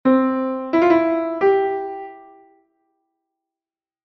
Se as notas rodean a principal, recibe o nome de semitrino e indícase con símbolos:
semitrino_ascendente.mp3